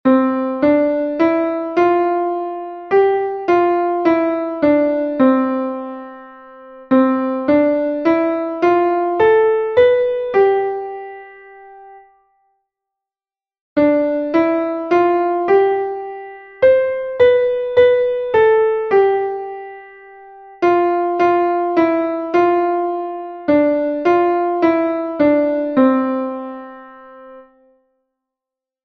Exercise 2: 6/8 time signature.